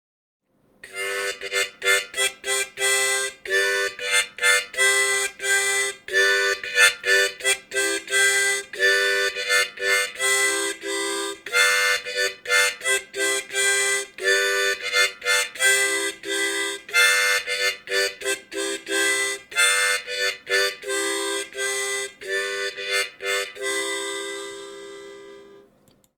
Не поём и не саморучно, но тем не менее.